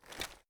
gear_rattle_weap_medium_02.ogg